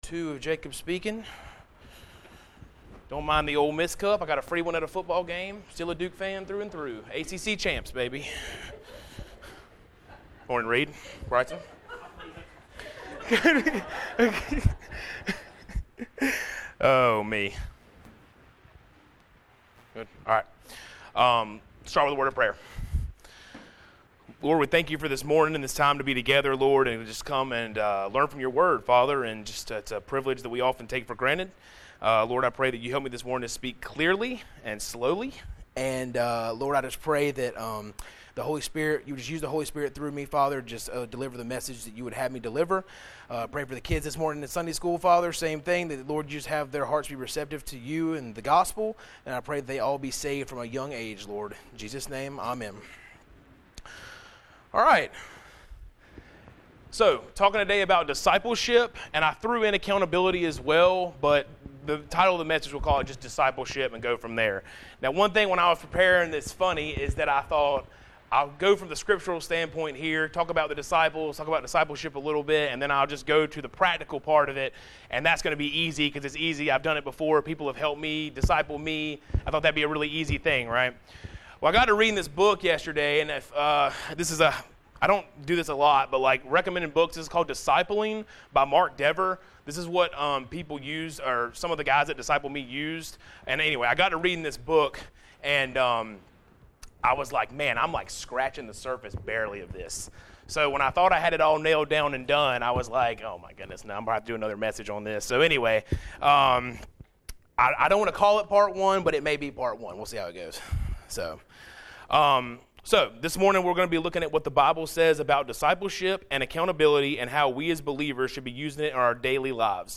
Passage: Matthew 4:18-22 Service Type: Sunday Morning Related « Holiness of God Are Denominations Unbiblical